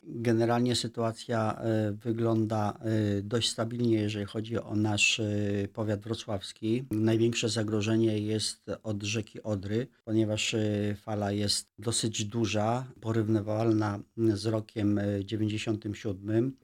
Hieronim Kuryś, czyli członek zarządu powiatu wrocławskiego był w poniedziałek gościem Radia Rodzina.